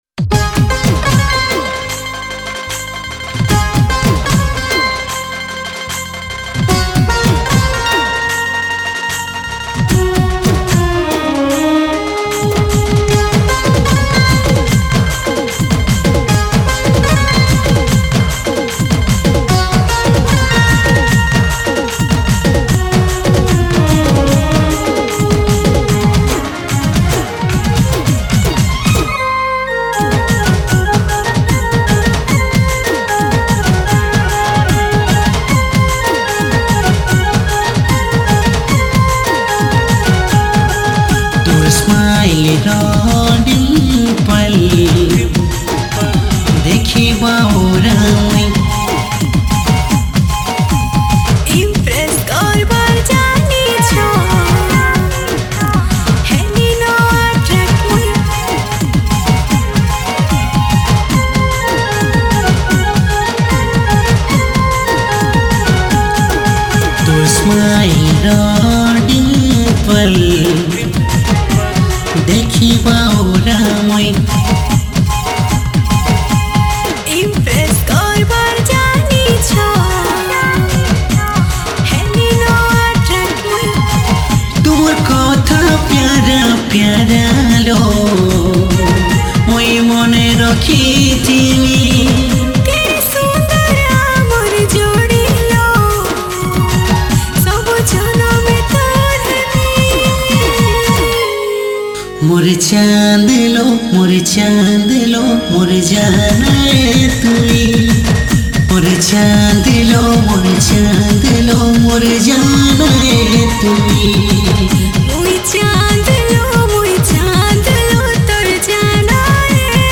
Sambapuri Single Song 2021